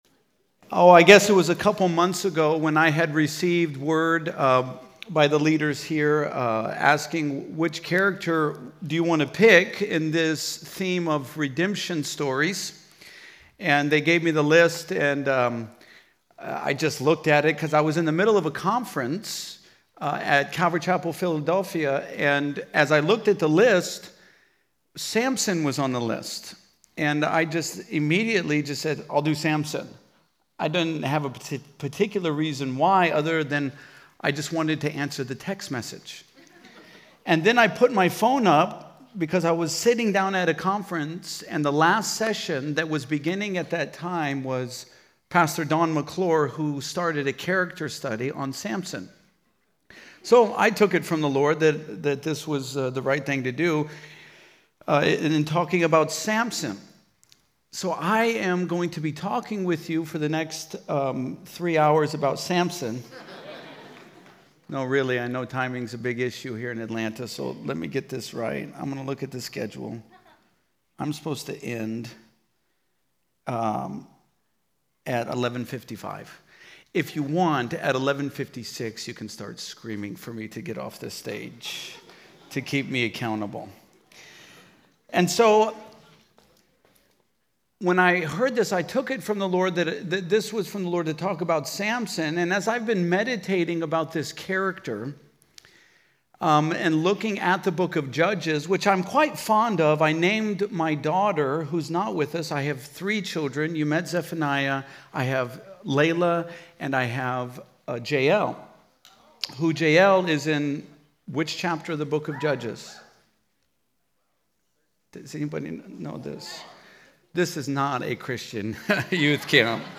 Home » Sermons » Redemption Stories: Samson
Conference: Youth Conference